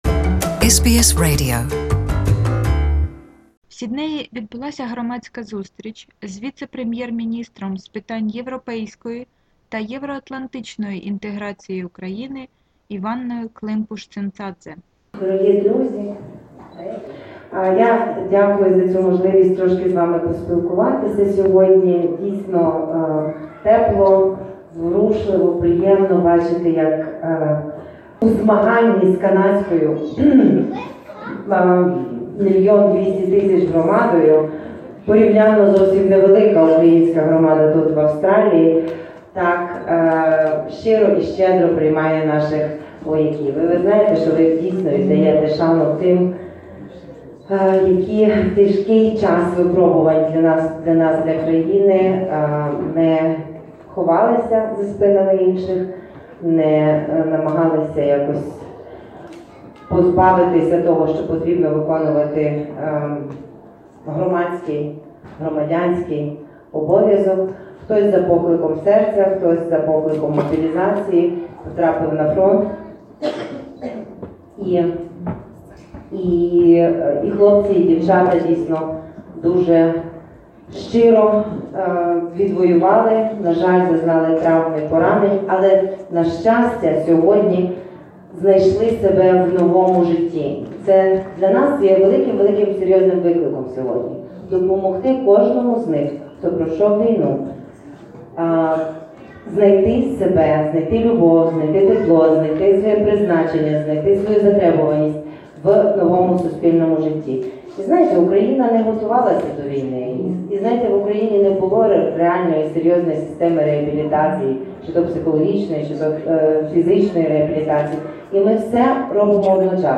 Public meeting with I Klympush Tsintsadze in Sydney